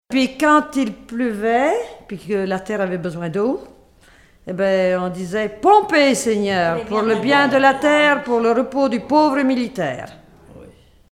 Mémoires et Patrimoines vivants - RaddO est une base de données d'archives iconographiques et sonores.
enfantine : comptine
Chansons traditionnelles